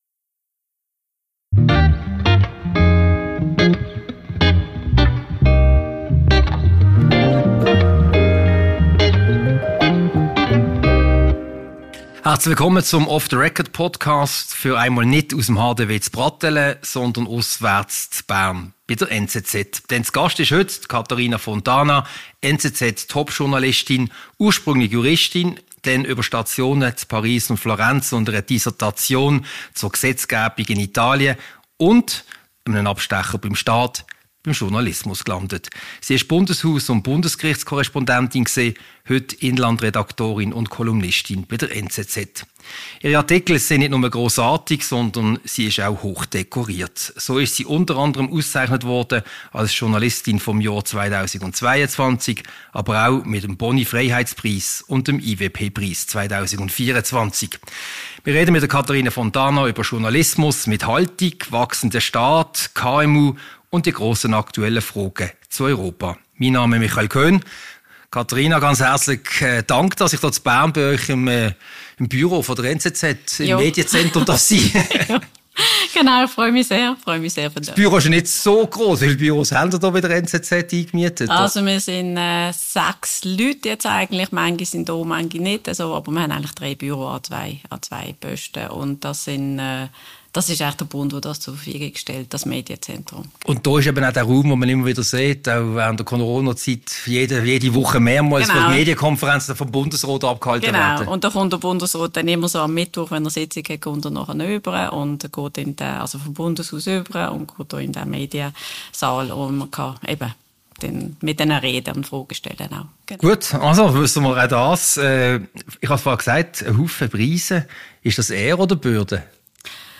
Ein Gespräch über den überbordenden Staat, über die politische Rolle des Bundesgerichts und aktuelle wirtschaftspolitische Themen wie die US-Zölle und die EU-Verträge. Diese Podcast-Ausgabe wurde in der NZZ-Redaktion in Bern aufgezeichnet.